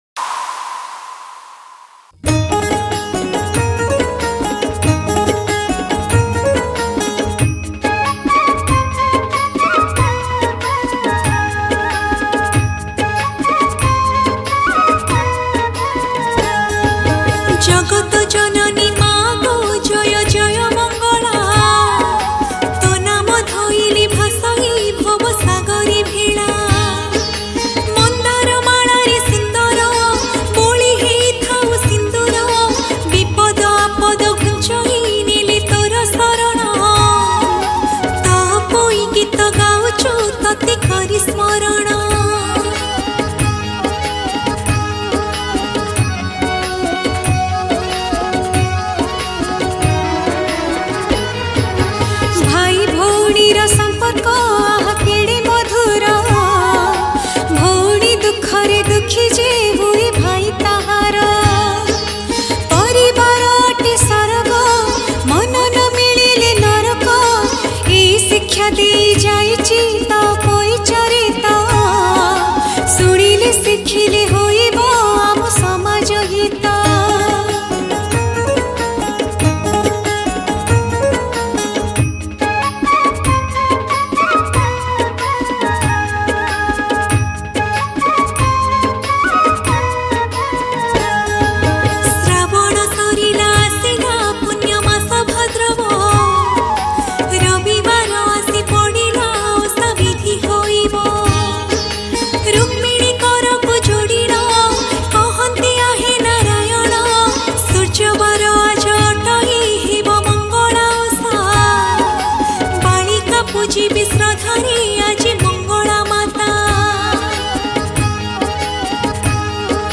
Bahi Gita Songs Download